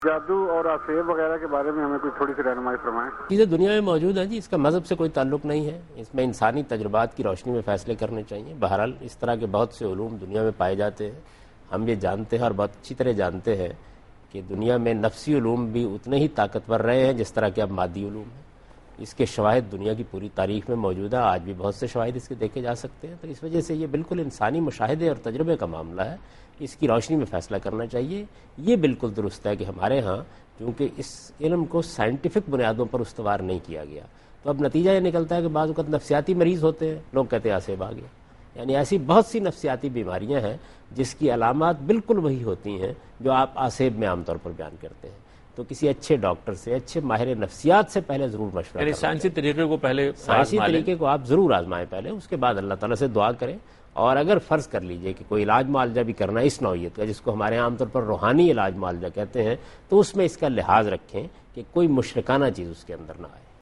Category: TV Programs / Dunya News / Deen-o-Daanish / Questions_Answers /
دنیا نیوز کے پروگرام دین و دانش میں جاوید احمد غامدی ”جادو اور آسیب“ سے متعلق ایک سوال کا جواب دے رہے ہیں